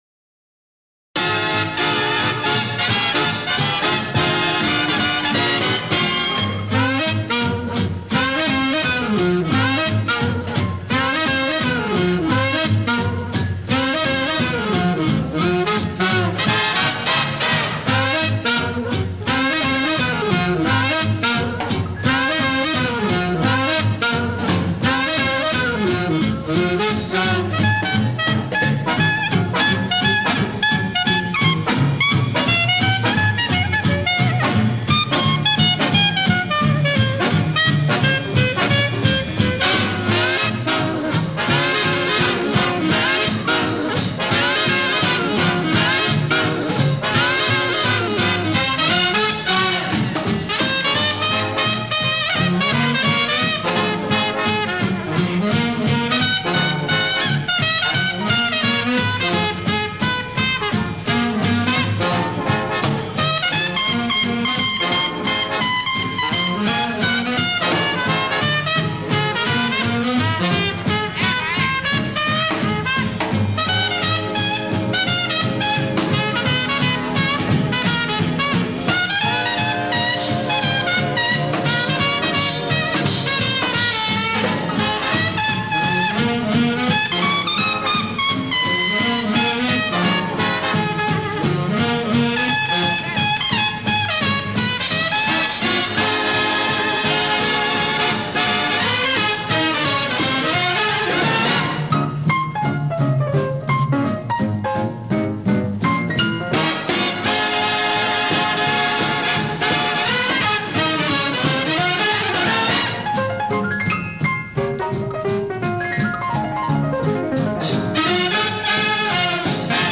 • Wartime Music: From patriotic anthems to sentimental ballads, the music of WWII reflects the emotions of a nation at war.
Big_Band-usradioguy_com.mp3